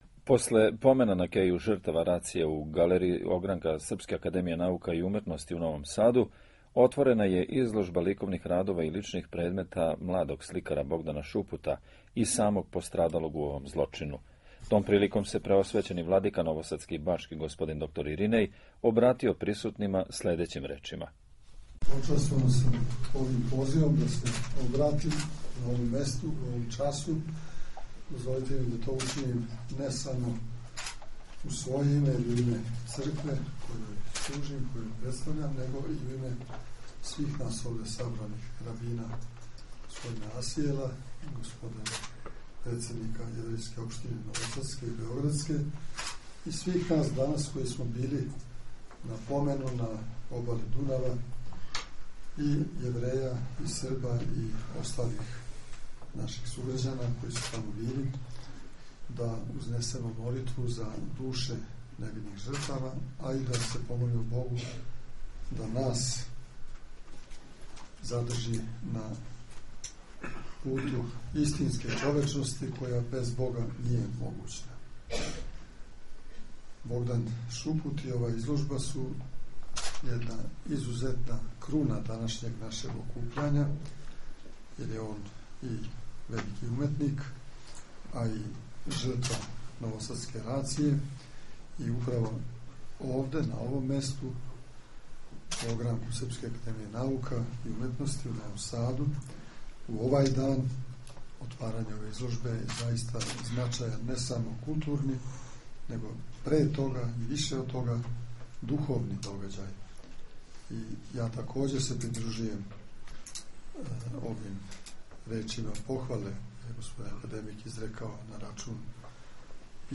После помена на Кеју жртава рације, у недељу, 23. јануара 2011. године, у Галерији огранка Српске Академије наука и уметности у Новом Саду отворена је изложба ликовних радова и личних предмета младог сликара Богдана Шупута, и самог пострадалог у овом злочину.
Том приликом се преосвећени Владика новосадски и бачки Господин Иринеј обратио присутнима својом беседом: